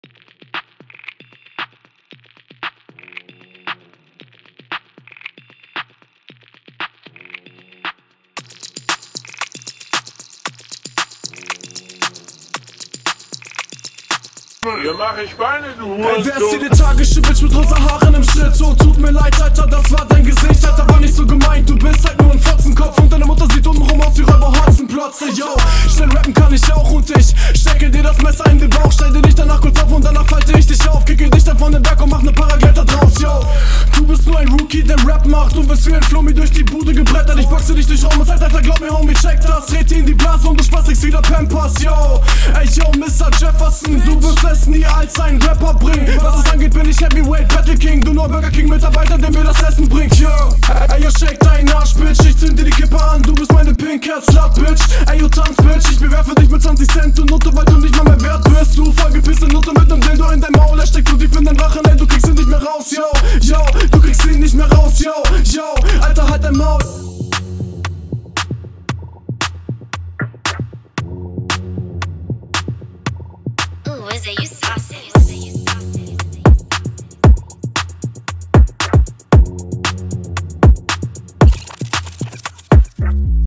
Auch wieder mit viel Energy in die nächste Runde .